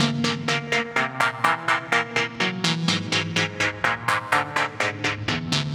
tx_synth_125_wholetone_C.wav